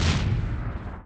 EXPLOSIO / SHIPS
1 channel